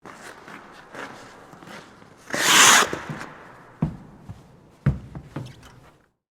Skate Slide into the Penalty Box
SFX
yt_FmDeX1i4pyI_skate_slide_into_the_penalty_box.mp3